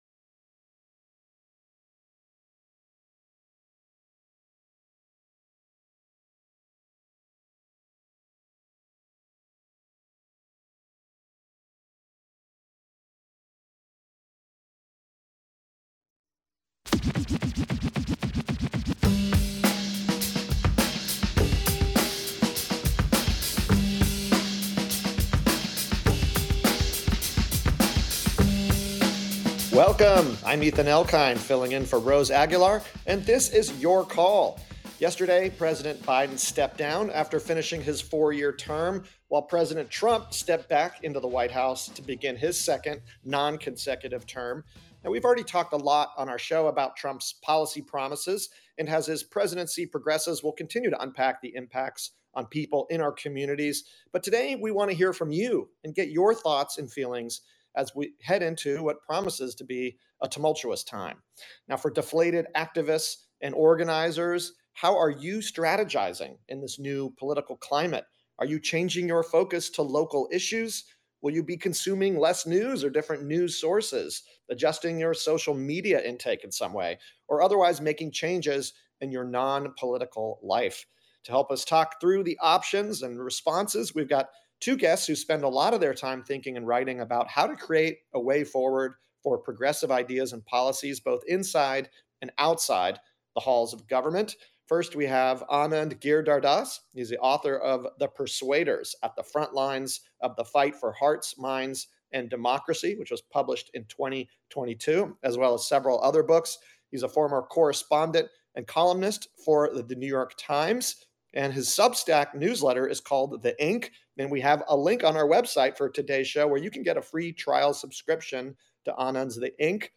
KALW's call-in show: Politics and culture, dialogue and debate.